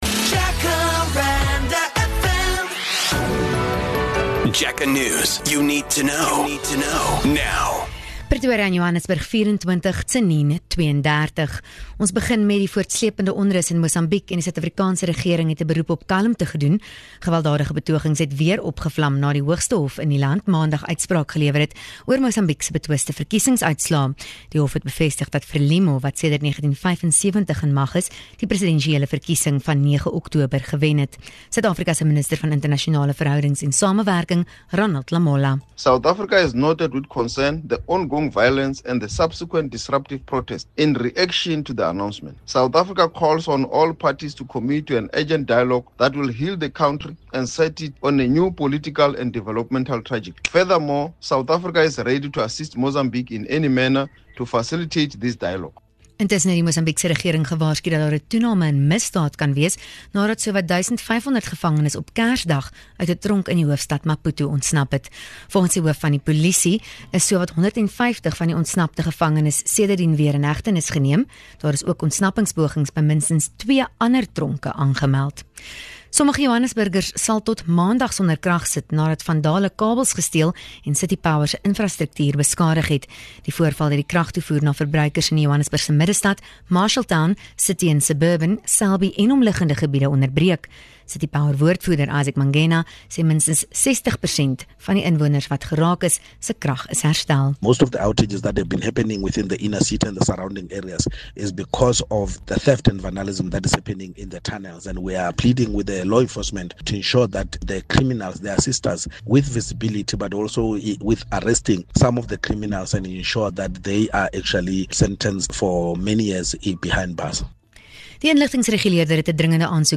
1 JacarandaFM News @ 11H01 4:30 Play Pause 16m ago 4:30 Play Pause Mais Tarde Mais Tarde Listas Like Curtido 4:30 Here's your latest Jacaranda FM News bulletin.